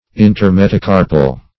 Search Result for " intermetacarpal" : The Collaborative International Dictionary of English v.0.48: Intermetacarpal \In`ter*me`ta*car"pal\, a. (Anat.) Between the metacarpal bones.